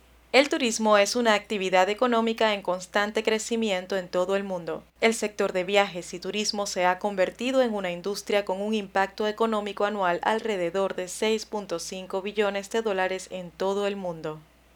Sprechprobe: Industrie (Muttersprache):
I really like the imitations of characters, I have good diction, pronunciation, voice projection and work with a neutral accent in Spanish and English.